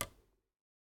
button_hover.ogg